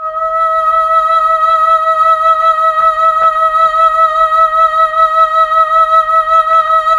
VOX_Chb Fm E_6-L.wav